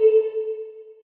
line_clear.ogg